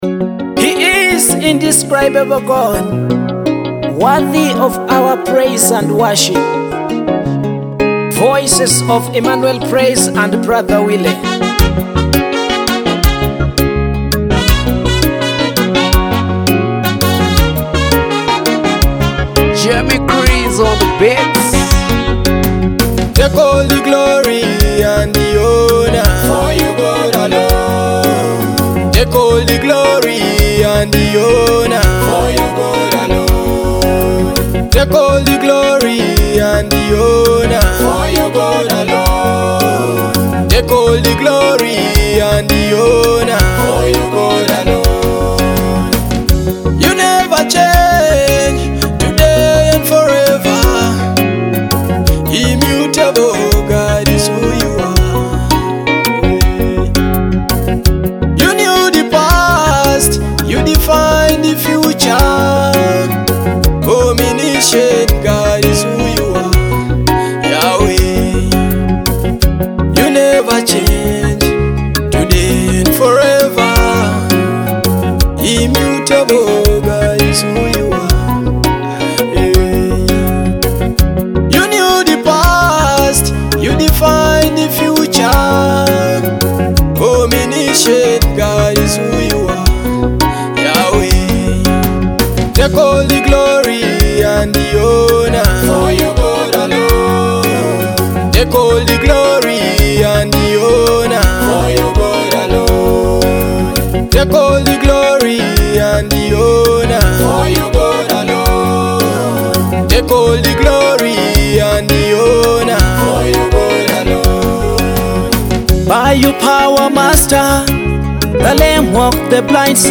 a soul-stirring gospel anthem celebrating God's majesty